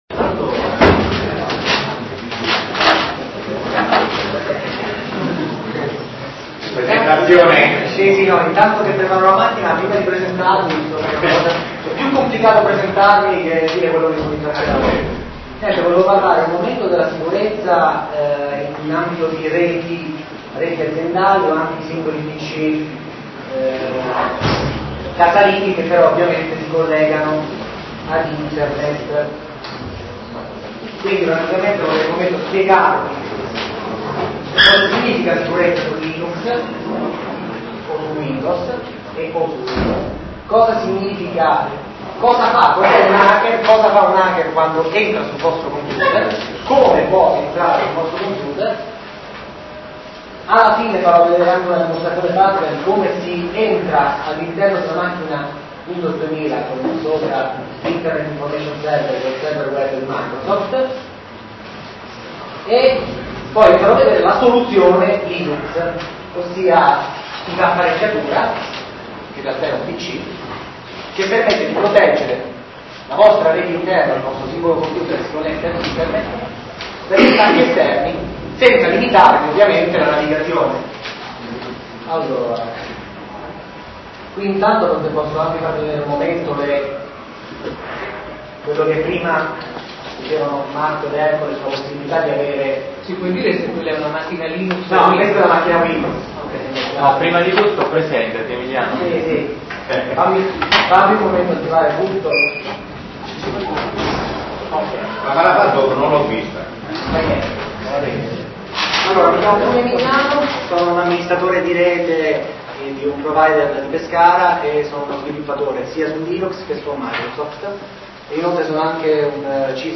Il LinuxDay organizzato dal Telug (Teramo Linux User Group) in contemporanea con gli altri LUG nazionali si è tenuto a Teramo il 01 Dicembre 2001 presso la scuola elementare "Risorgimento".